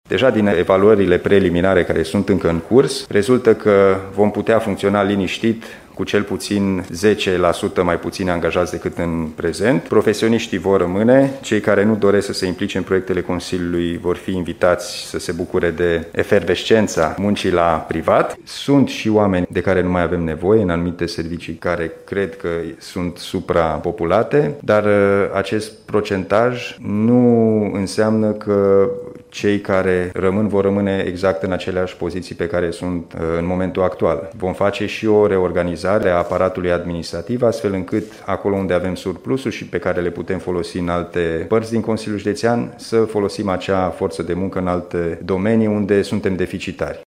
Intenția noii conduceri este de a face o reorganizare a aparatului administrativ, spune Alin Nica: